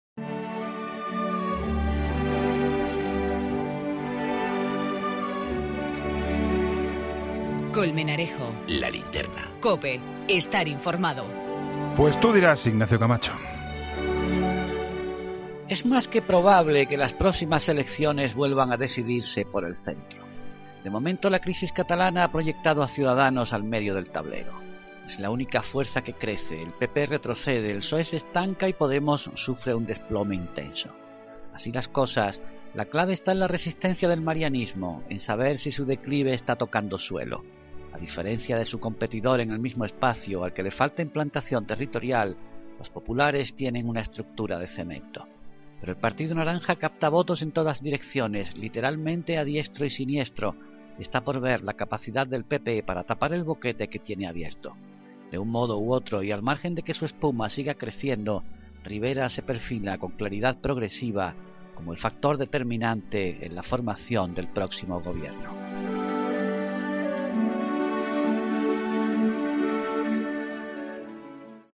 Ignacio Camacho analiza en 'La Linterna' los resultados del último barómetro del CIS.